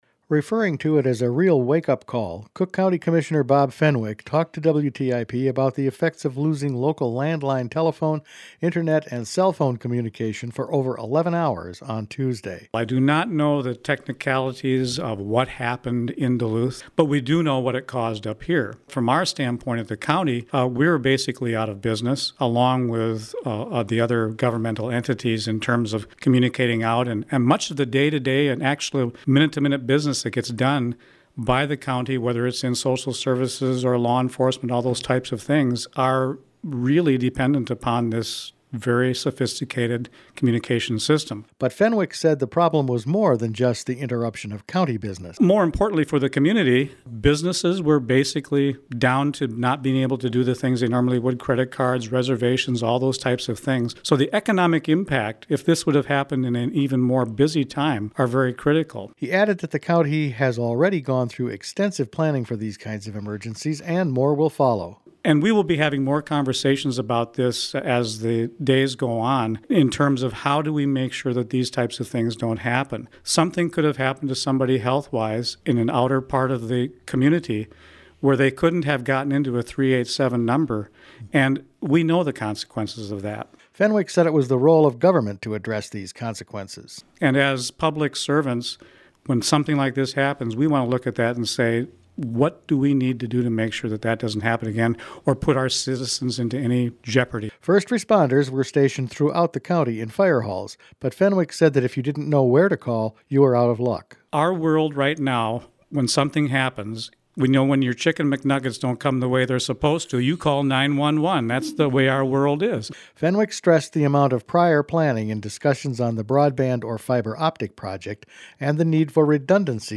Referring to it as a real wake-up call, Cook County Commissioner Bob Fenwick talked to WTIP about the effects of losing landline telephone, internet and cell phone communication for over 11 hours on Tuesday.